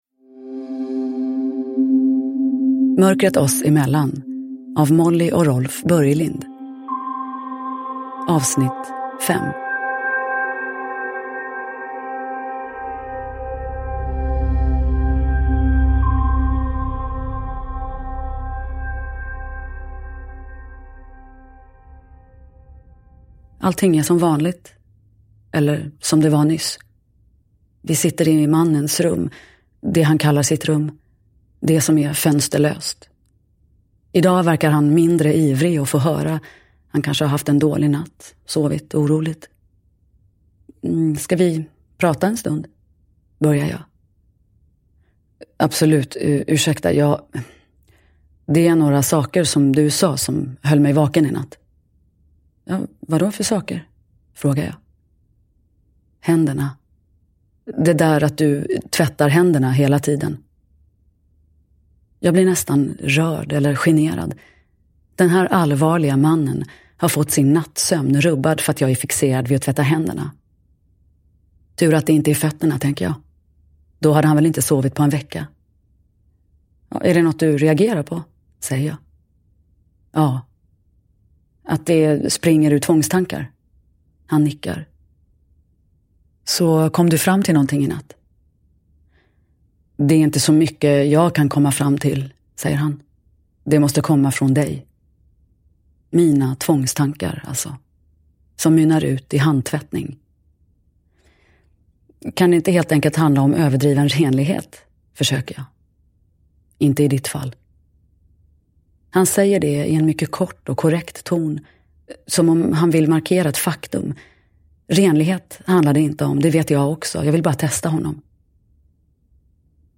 Mörkret oss emellan. 5 – Ljudbok – Laddas ner
Uppläsare: Nina Zanjani